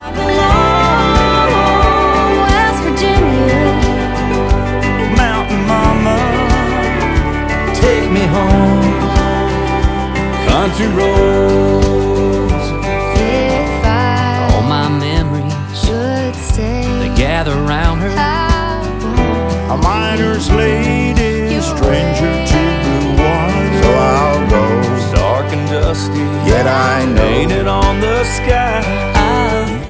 • Country
mashup